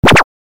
دانلود صدای موس 8 از ساعد نیوز با لینک مستقیم و کیفیت بالا
جلوه های صوتی
برچسب: دانلود آهنگ های افکت صوتی اشیاء دانلود آلبوم صدای کلیک موس از افکت صوتی اشیاء